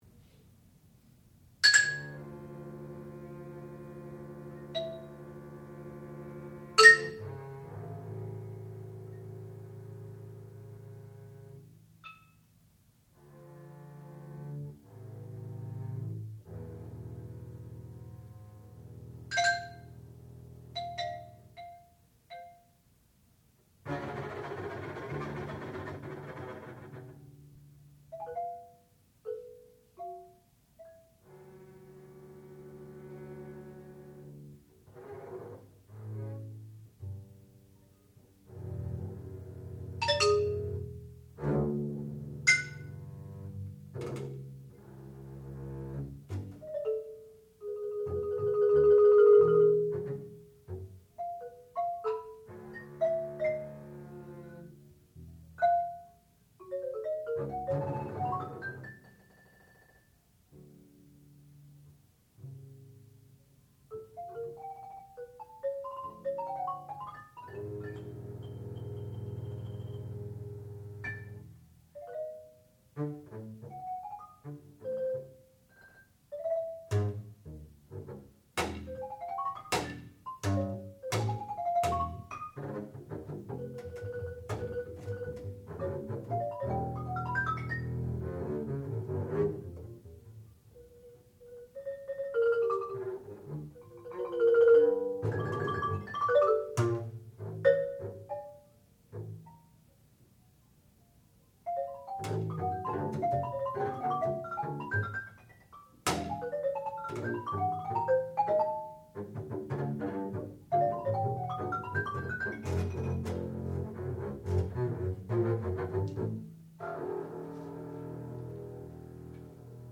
sound recording-musical
classical music
Qualifying Recital
double bass